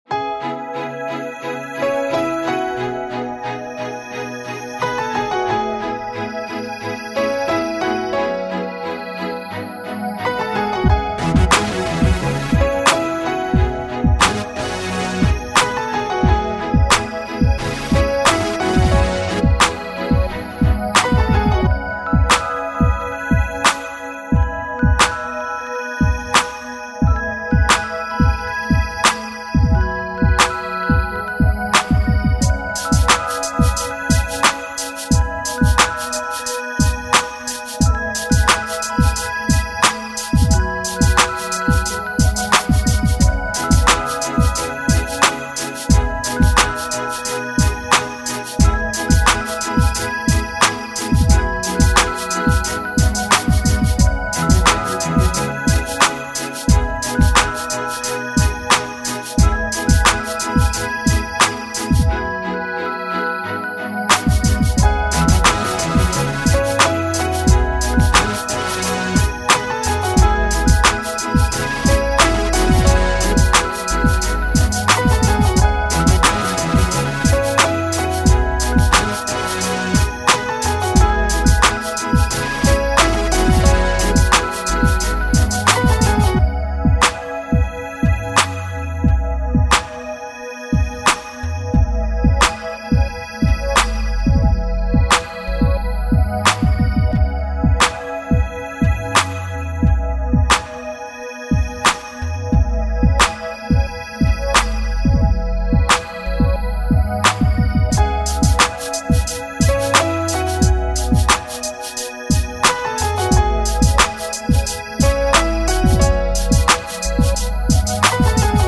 An urban, airy track featuring electric piano and... more »